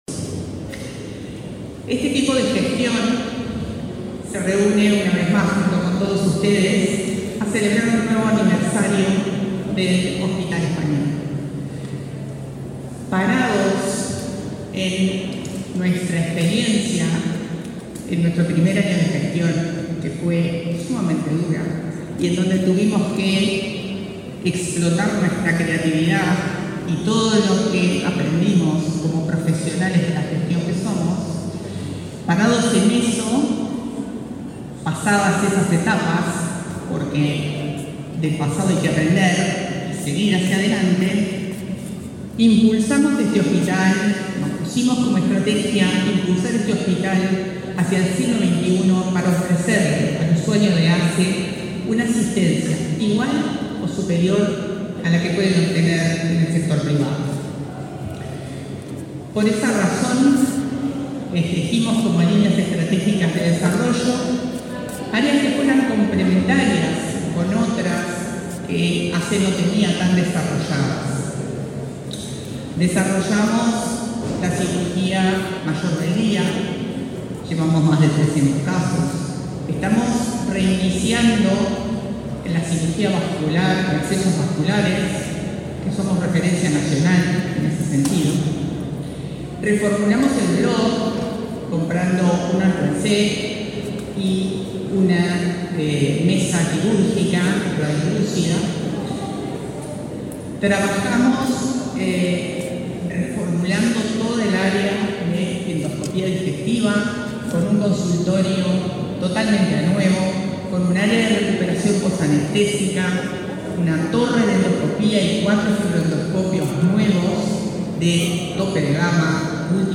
Palabra de autoridades en acto aniversario del Hospital Español